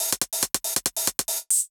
UHH_ElectroHatA_140-01.wav